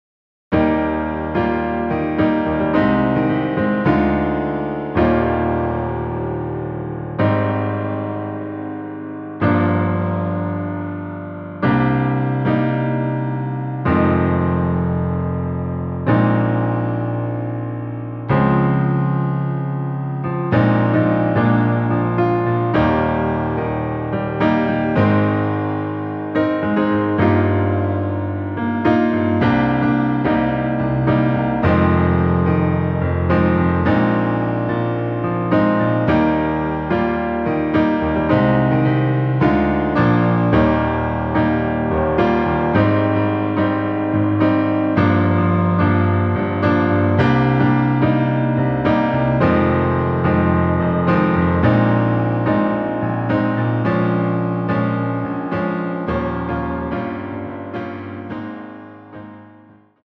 노래 바로 시작 하는 곡이라 전주 2마디 만들어 놓았습니다.(미리듣기 참조)
Eb
앞부분30초, 뒷부분30초씩 편집해서 올려 드리고 있습니다.
중간에 음이 끈어지고 다시 나오는 이유는